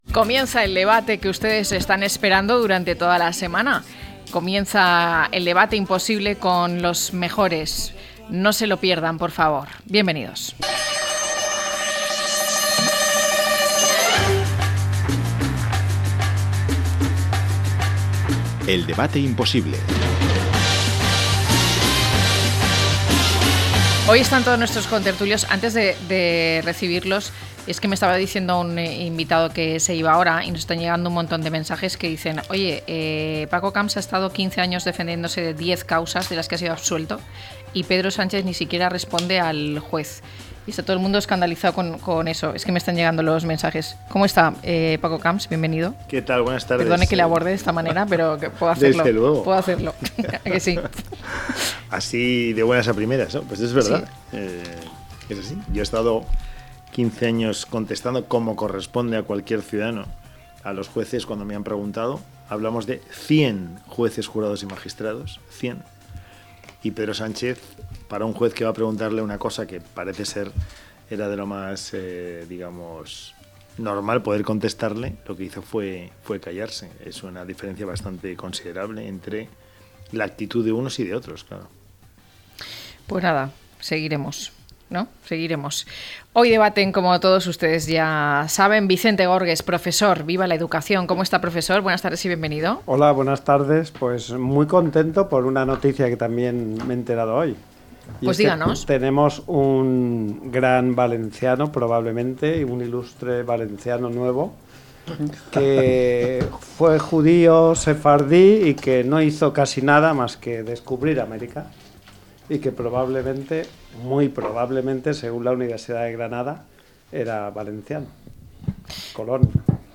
Turismo de crímenes y sucesos, a debate - La tarde con Marina
1014-LTCM-DEBATE.mp3